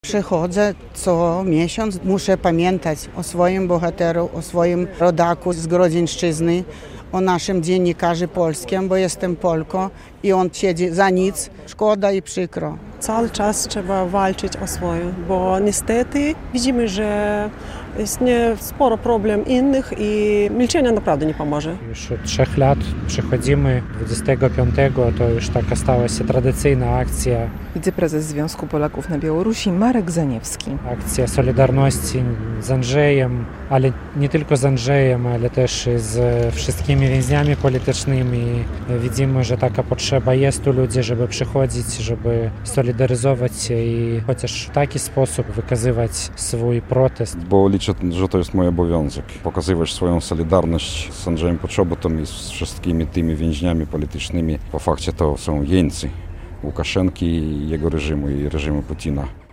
"Wolność dla Andrzeja Poczobuta" i "Uwięziony za polskość". Z takimi hasłami manifestowali w centrum Białegostoku uczestnicy akcji solidarności z dziennikarzem na Białorusi Andrzejem Poczobutem.